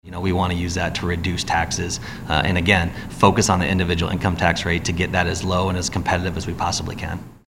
And Senate Republican Leader Jack Whitver of Ankeny says the surplus is expected to double to more than two billion dollars by this summer.